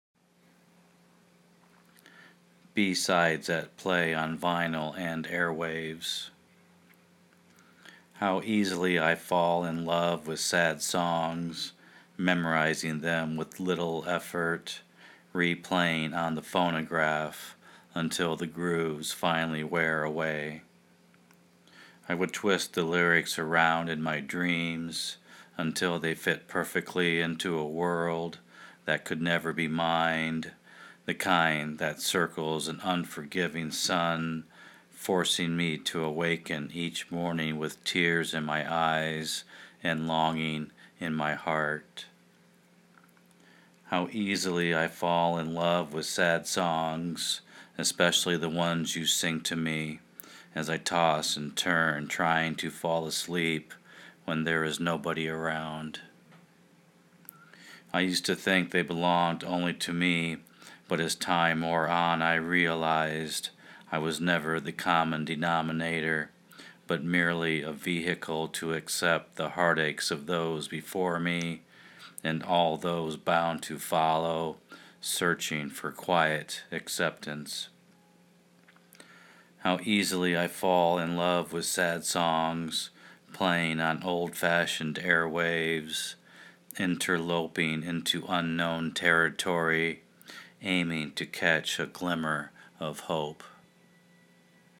And I loved the recitation.